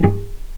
vc_pz-G#4-pp.AIF